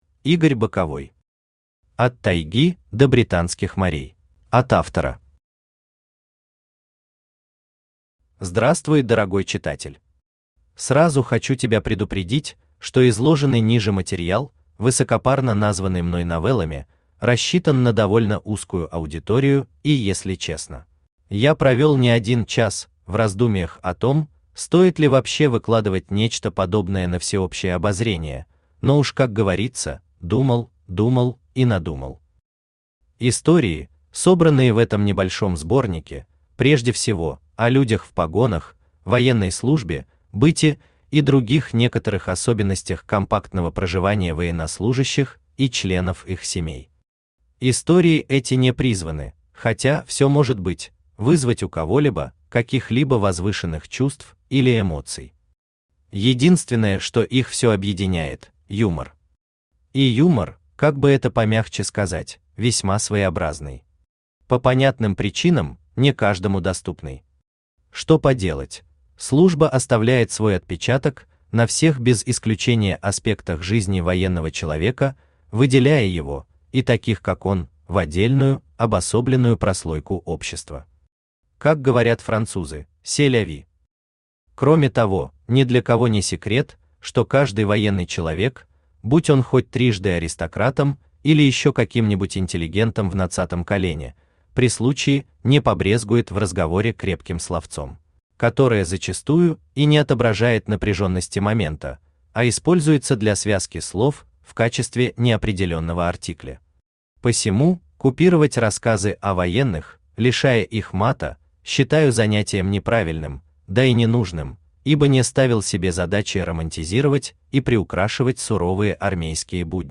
Автор Игорь Евгеньевич Боковой Читает аудиокнигу Авточтец ЛитРес.